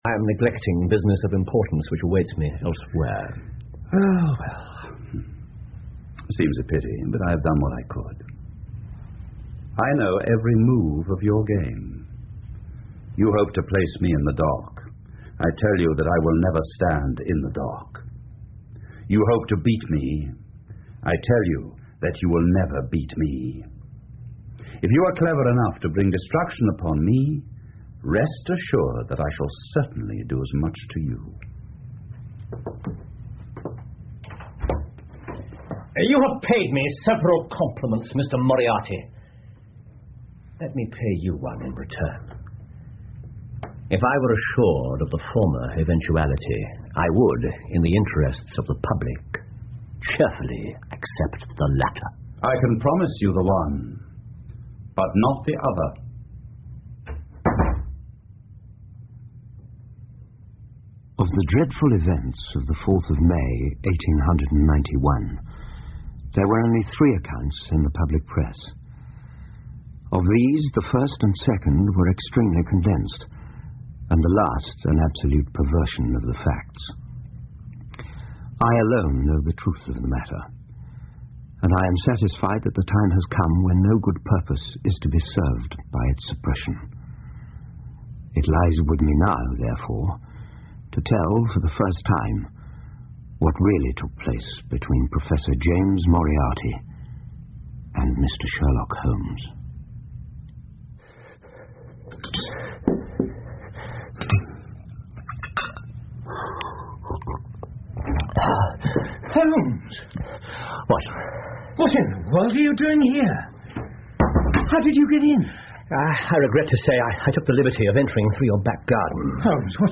福尔摩斯广播剧 The Final Problem 3 听力文件下载—在线英语听力室